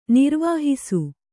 ♪ nirvāhisu